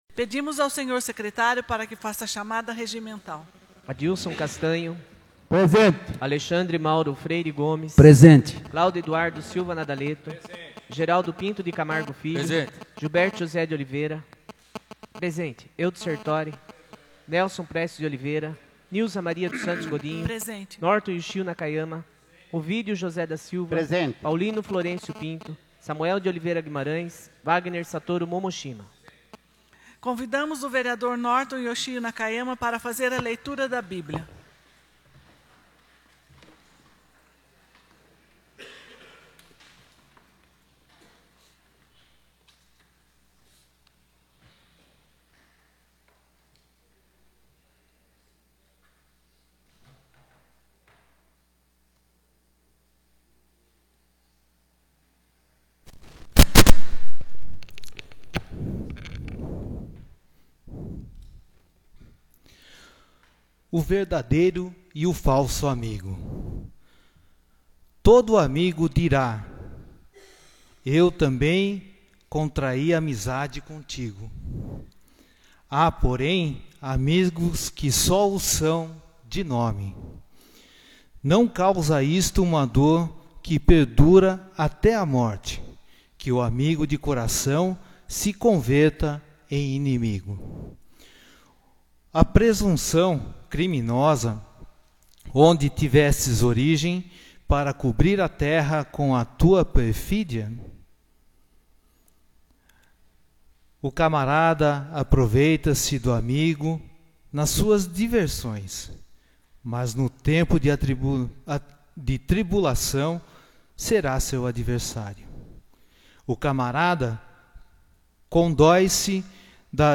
16ª Sessão Ordinária de 2014